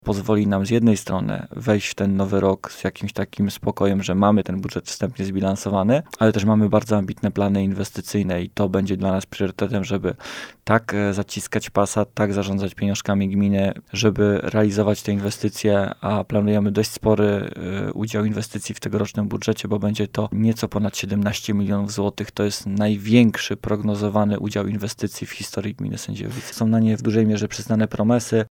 Jak jednak przekonuje Dariusz Cieślak, wójt gminy Sędziejowice, gdyby nie dotacje, trudno byłoby myśleć o remontach, czy budowach.